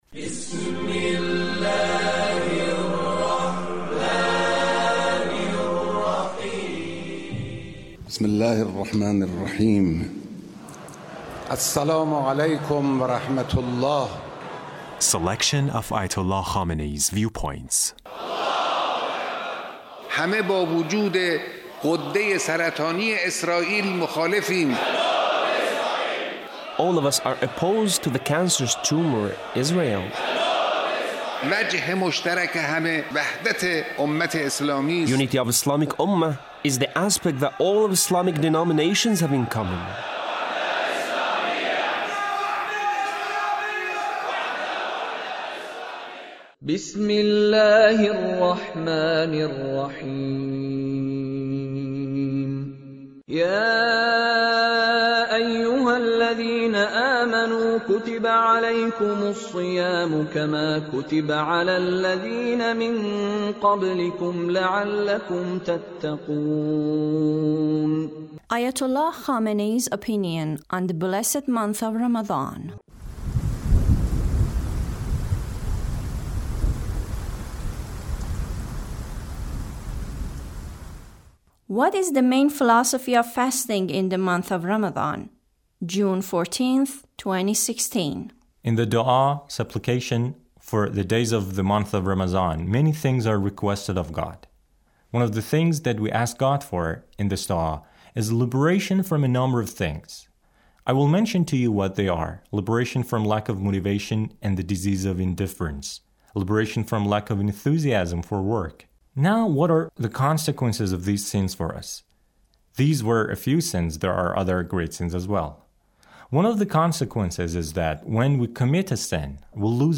Leader's speech (39)